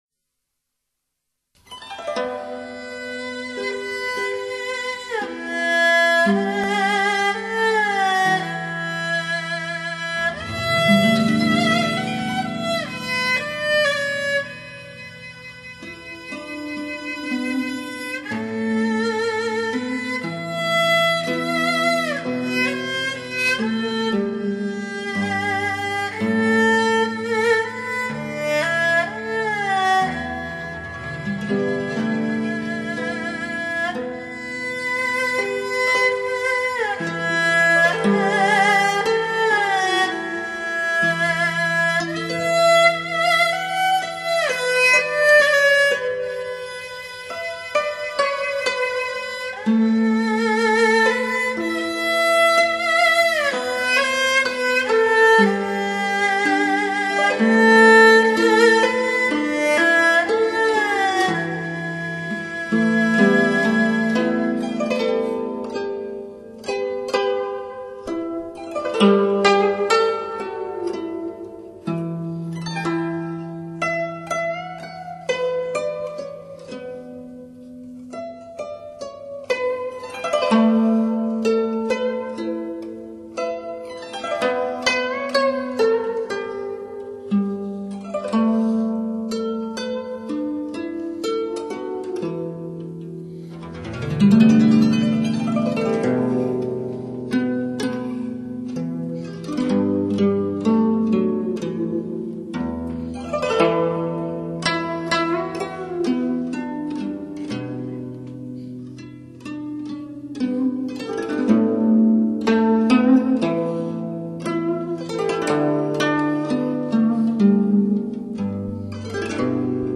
版    本：HDCD
专辑歌手：纯音乐
流行古典风尚重现，民族乐韵色彩闪烁。
琴声响起，悠扬的旋律伴随着缕缕氤氲，满室生香，温情四溢......